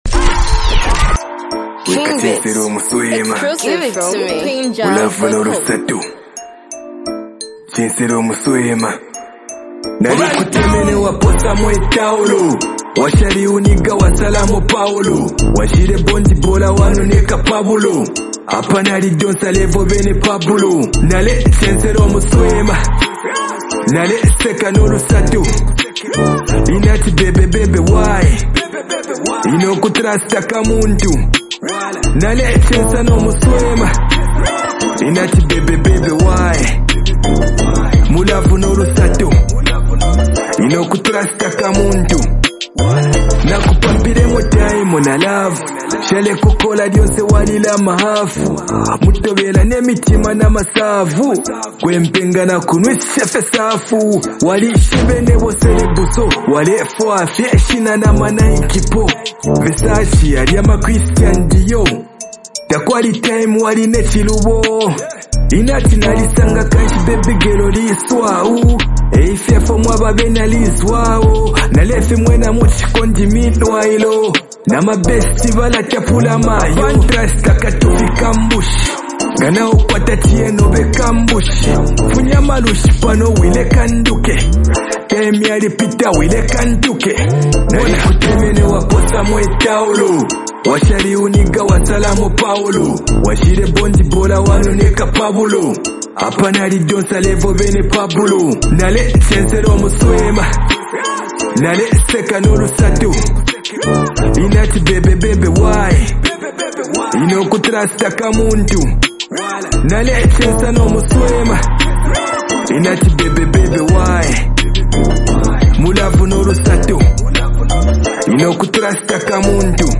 is a deeply emotional love song